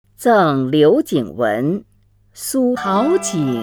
虹云朗诵：《赠刘景文》(（北宋）苏轼) （北宋）苏轼 名家朗诵欣赏虹云 语文PLUS